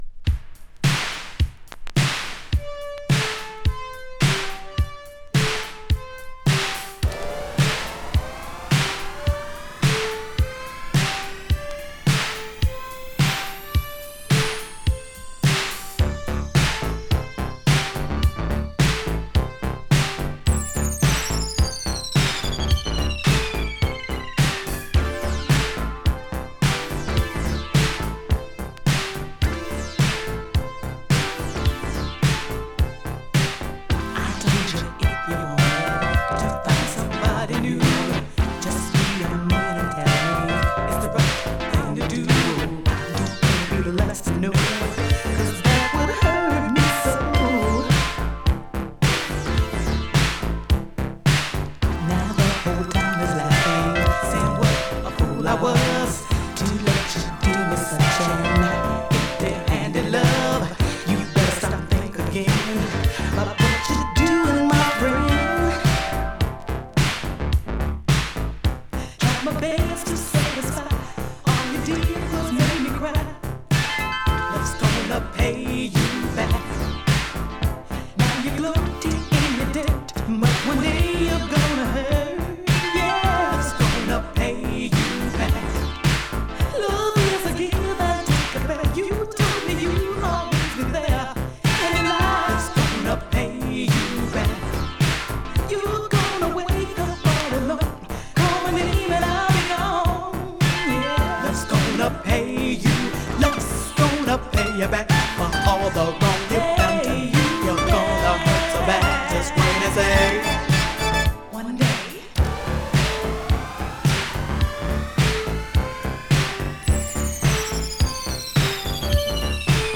【BOOGIE】 【DISCO】
エレクトリック・ブギー！